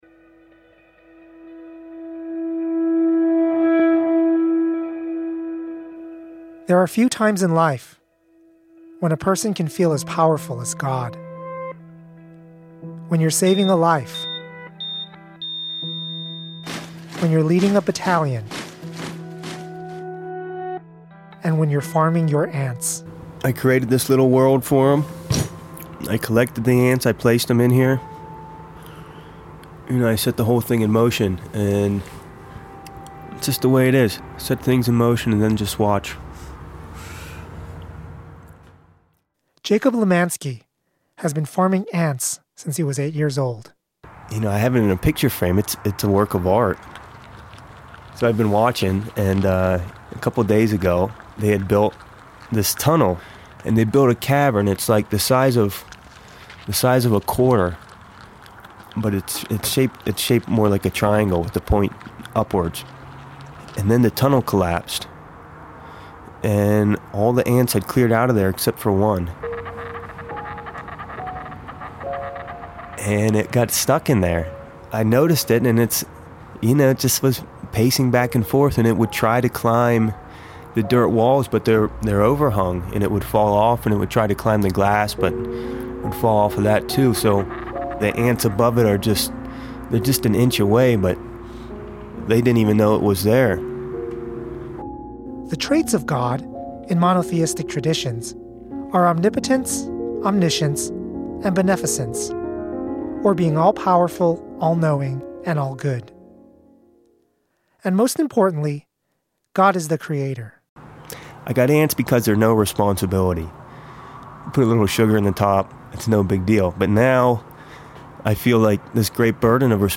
Soundtrack: